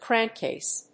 音節cránk・càse
アクセント・音節cránk・càse